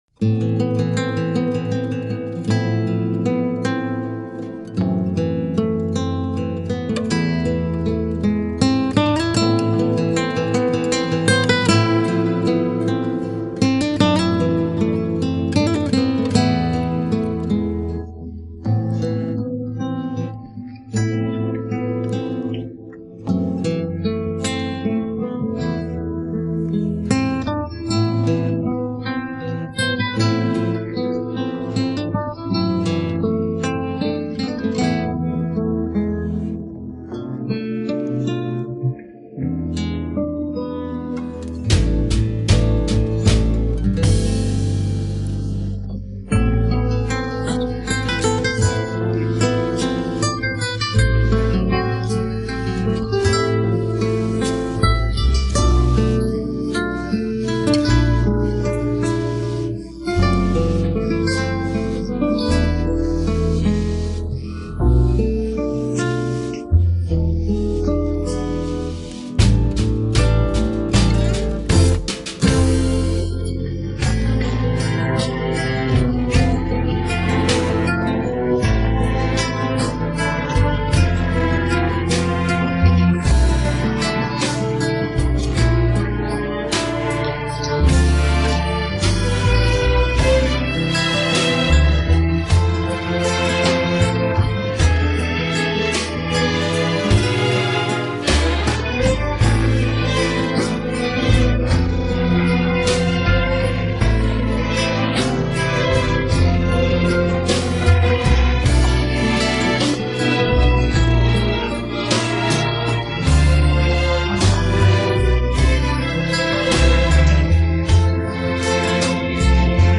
پخش نسخه بی‌کلام